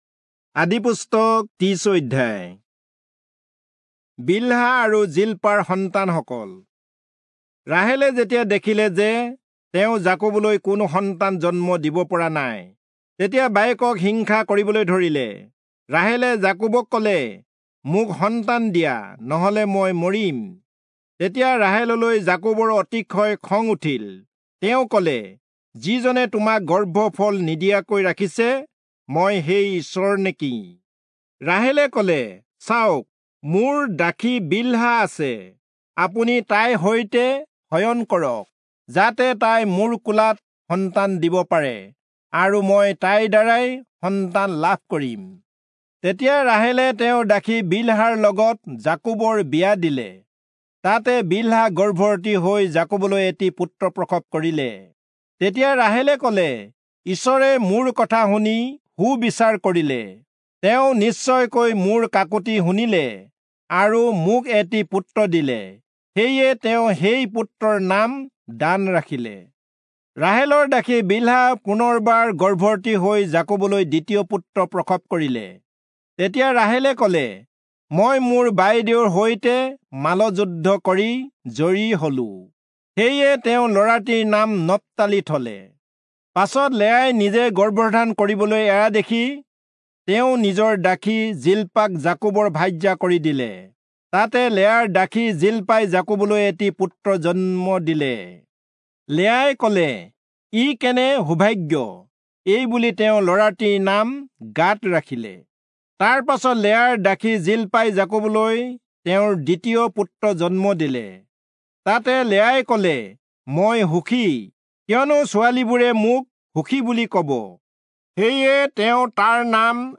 Assamese Audio Bible - Genesis 6 in Tev bible version